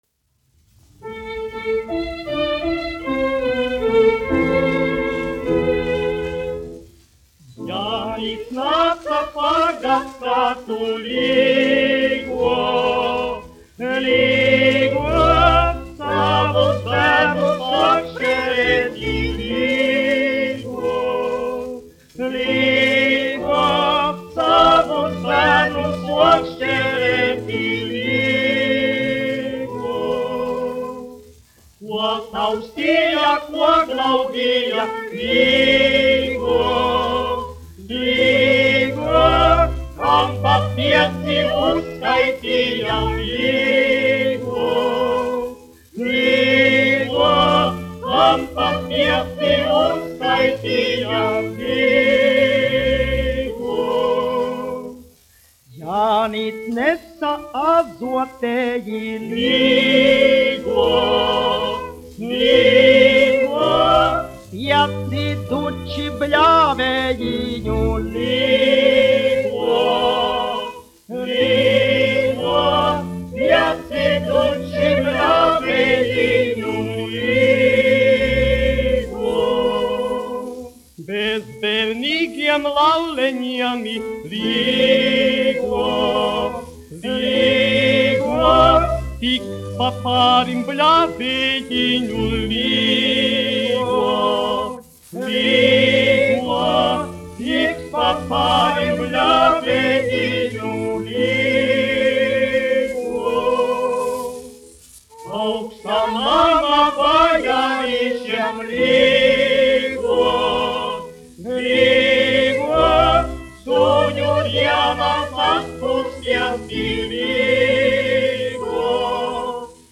1 skpl. : analogs, 78 apgr/min, mono ; 25 cm
Latviešu tautasdziesmas
Vokālie kvarteti